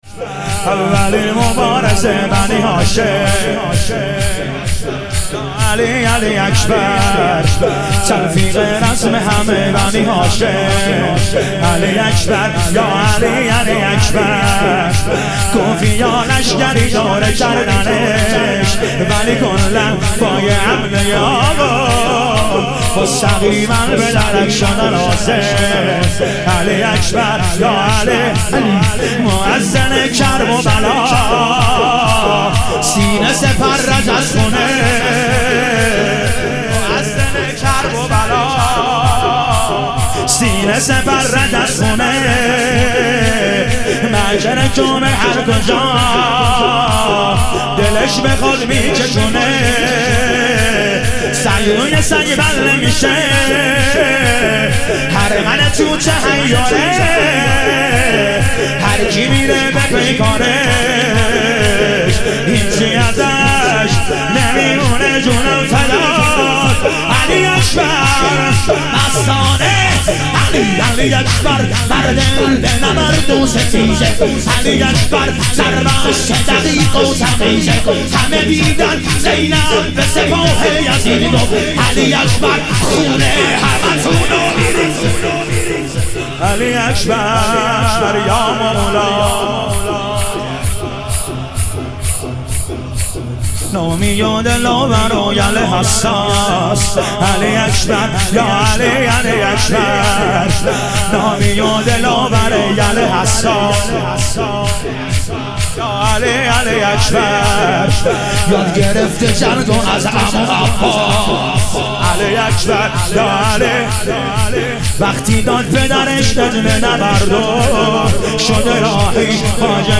اربعین امام حسین علیه السلام - شور